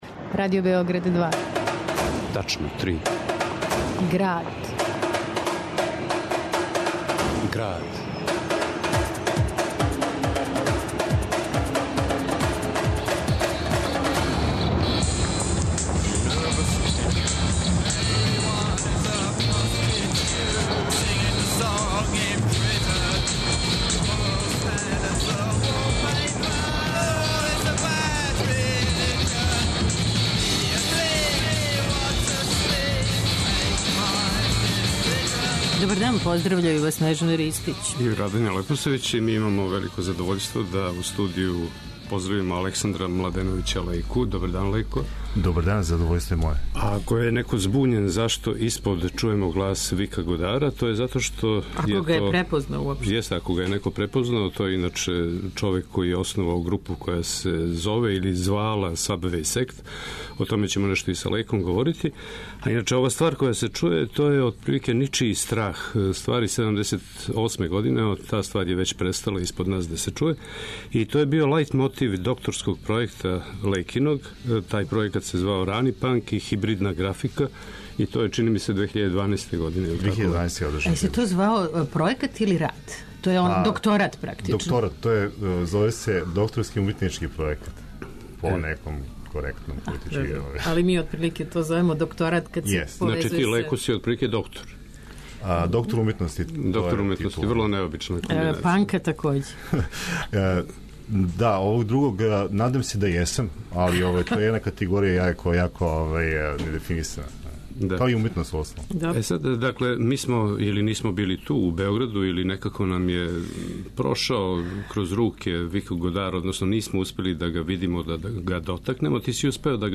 Наравно, уз доста музике.